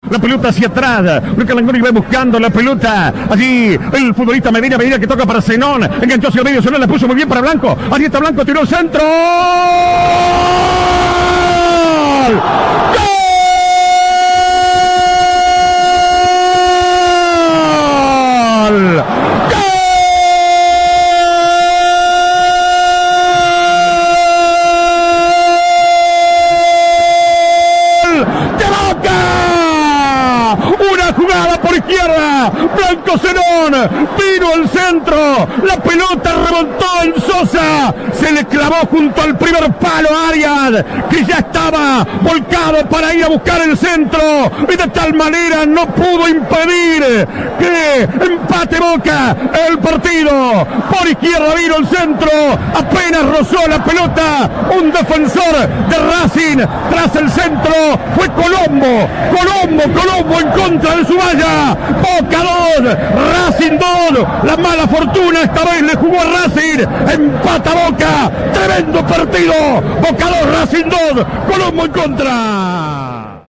Show de goles en "La Bombonera": reviví el relato de los seis tantos del clásico